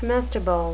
comestible (kuh-MES-ti-buhl) adjective
Pronunciation: